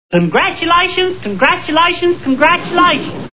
Gomer Pyle U.S.M.C. TV Show Sound Bites